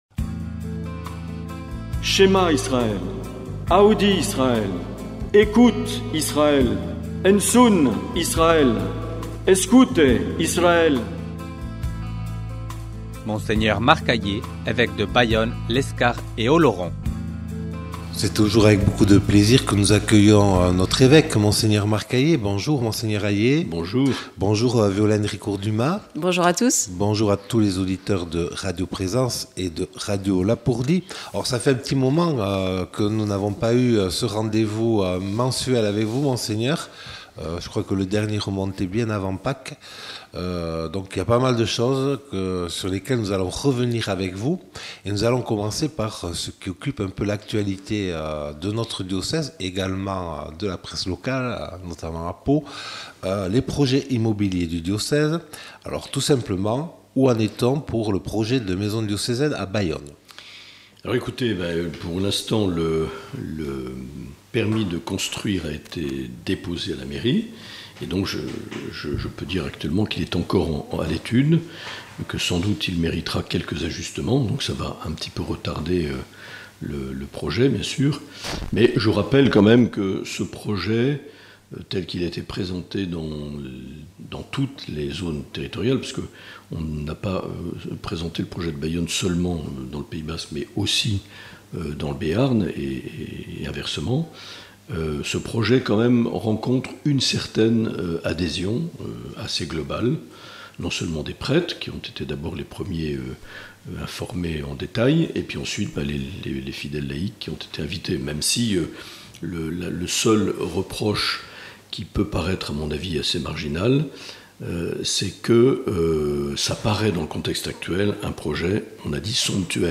L’entretien avec Mgr Aillet - Juin 2023